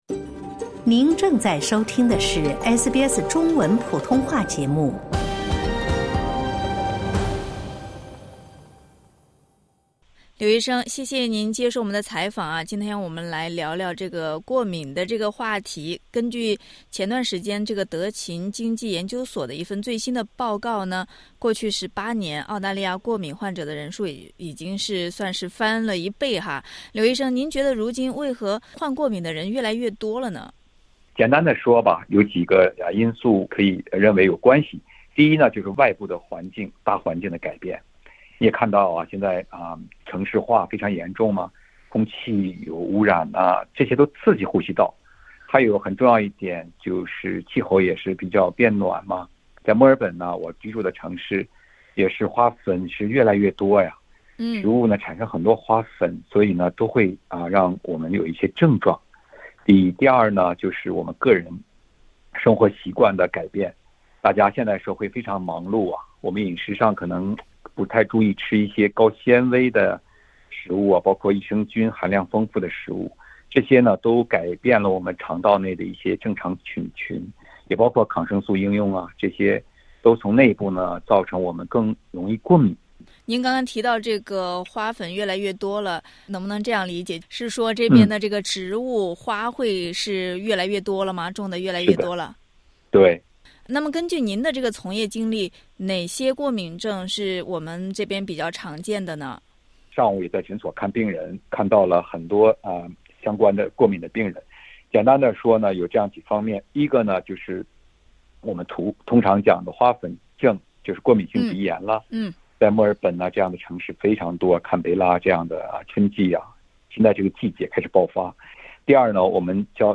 花粉季节来临，医生有哪些花粉症预防建议？点击音频收听采访。